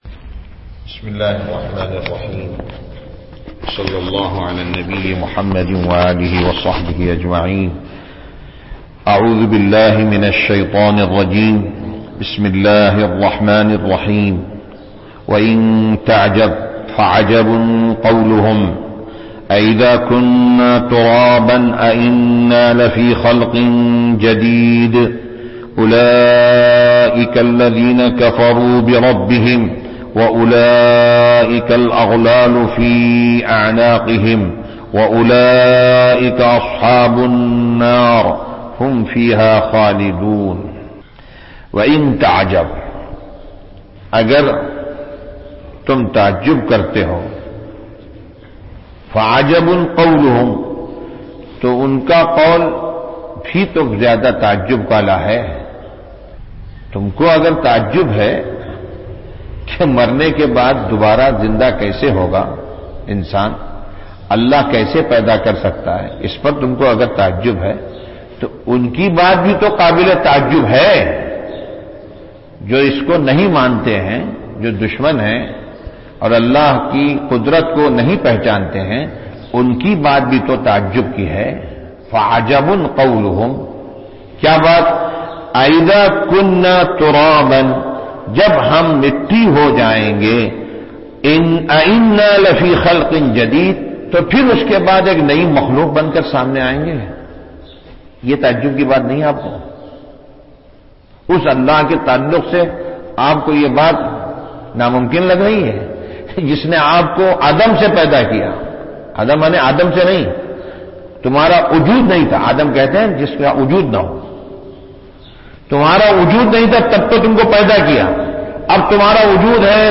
درس قرآن نمبر 1112